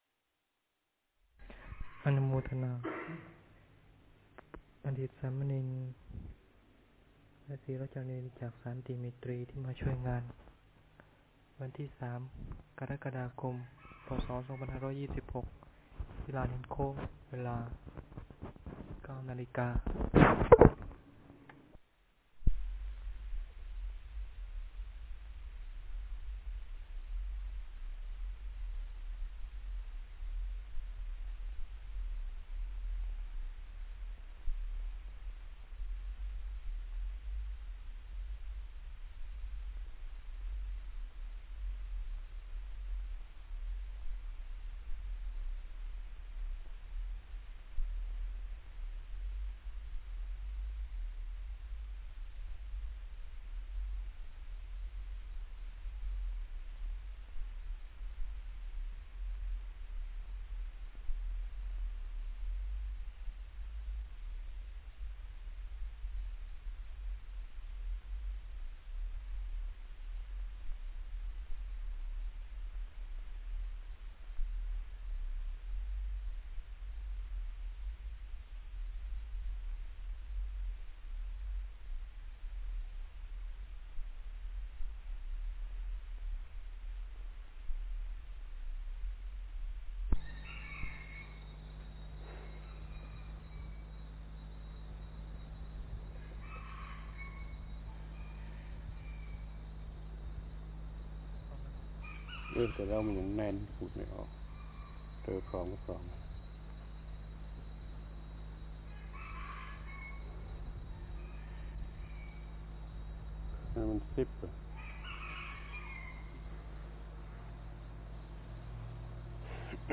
ฟังธรรมะ Podcasts กับ พระธรรมโกศาจารย์ (พุทธทาสภิกขุ)